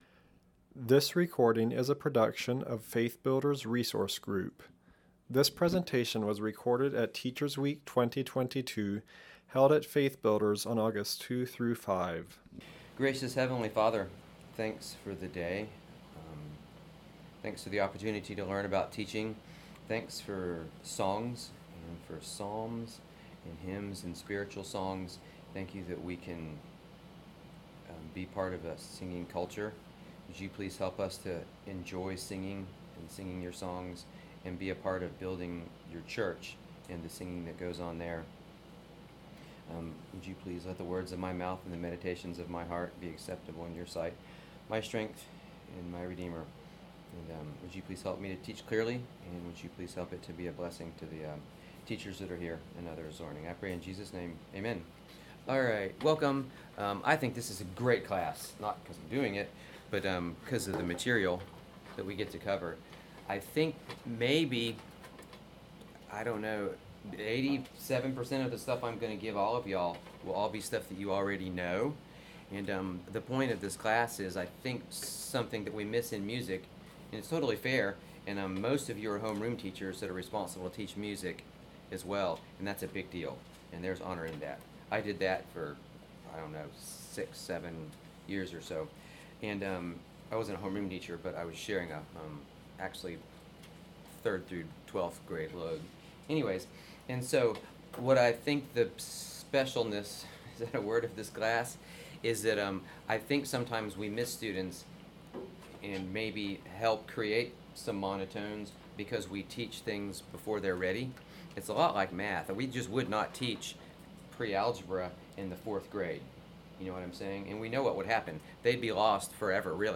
Home » Lectures » Age-appropriate A Cappella Activities